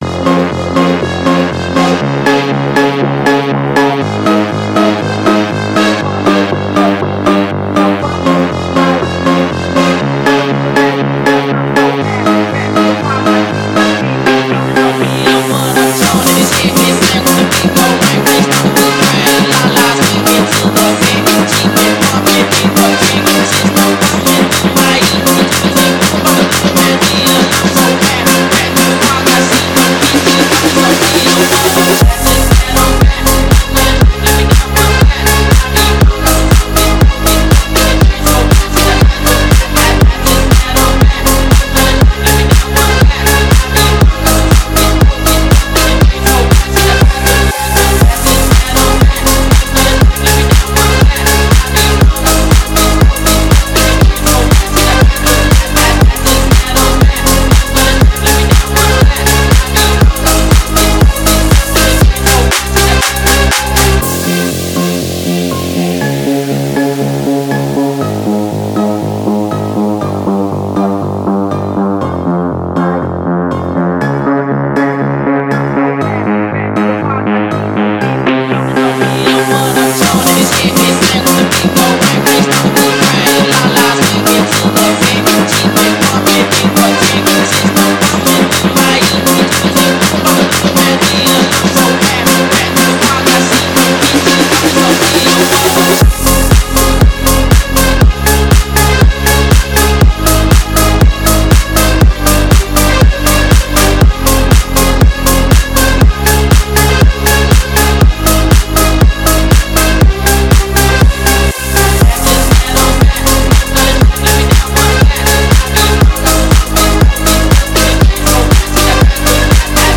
Фонк музыка
Phonk музыка